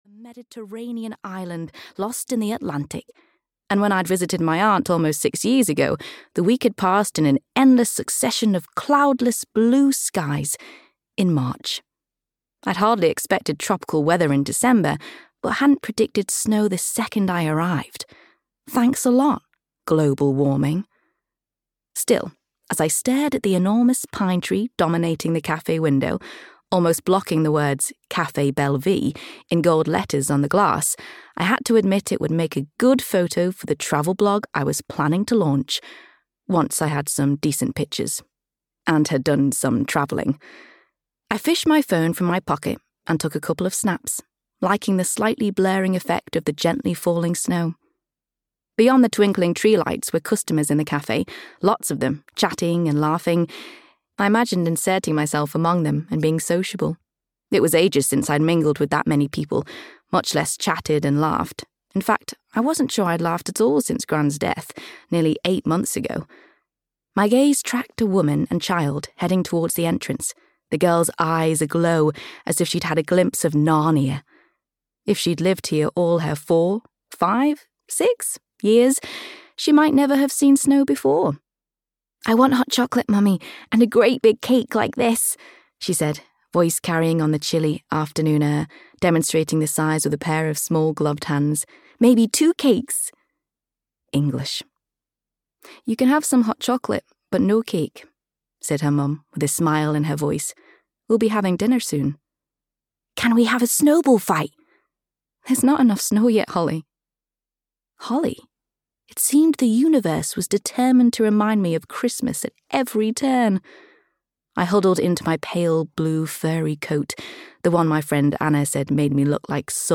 I'll Be Home for Christmas (EN) audiokniha
Ukázka z knihy